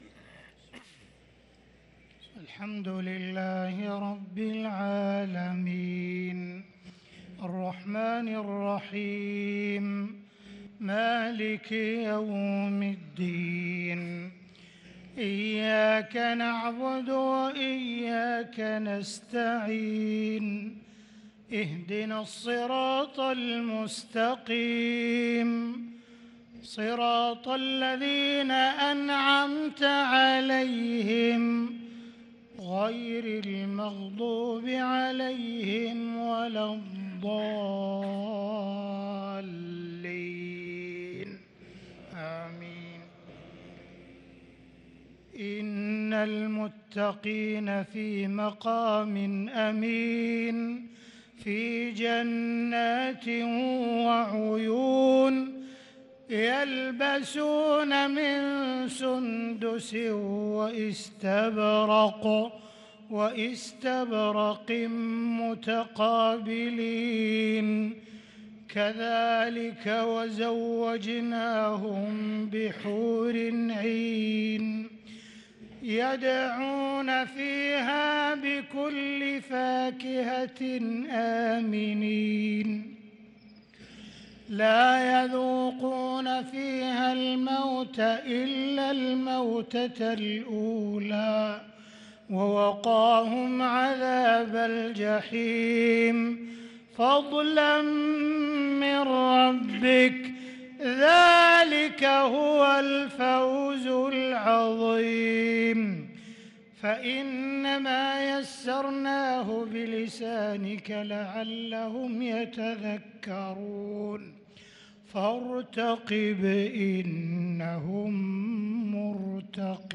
صلاة المغرب للقارئ عبدالرحمن السديس 24 رمضان 1443 هـ
تِلَاوَات الْحَرَمَيْن .